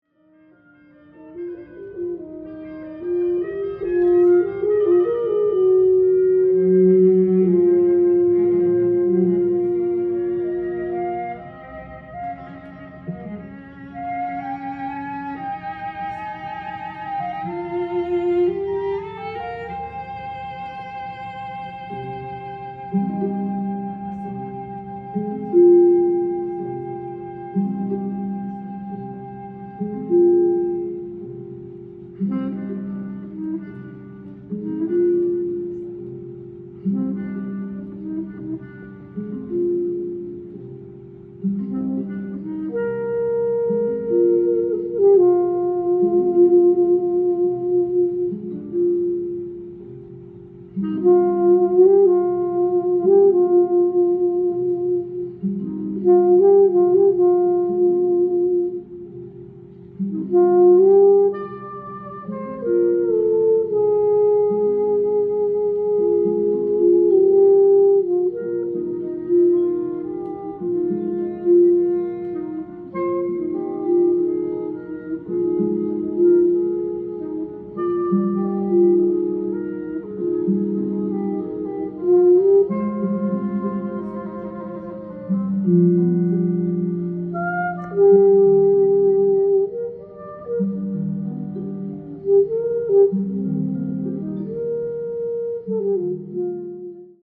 ジャンル：JAZZ-ALL
店頭で録音した音源の為、多少の外部音や音質の悪さはございますが、サンプルとしてご視聴ください。
リリカルなアルトを一層引き立てる優美なストリングスとの相性は抜群です。
ステレオならではの広がる臨場感と融和する端正な魅力はモノラルとはまた違う迫力があります！！